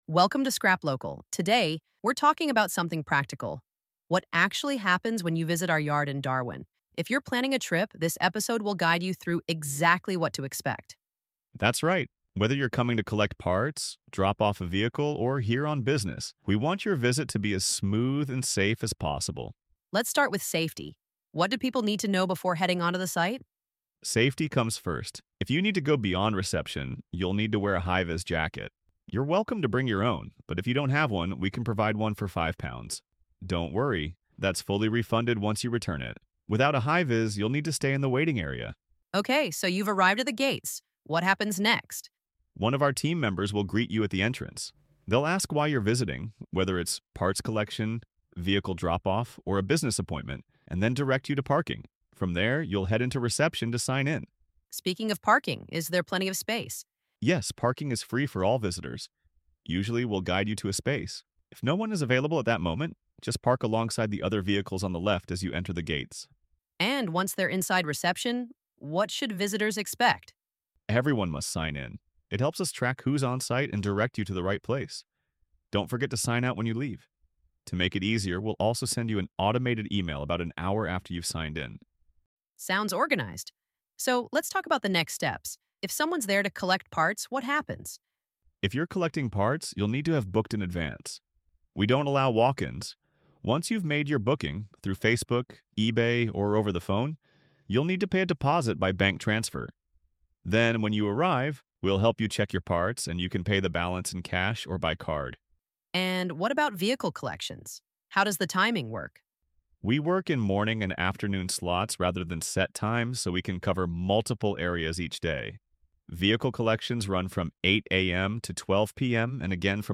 ai-podcast.mp3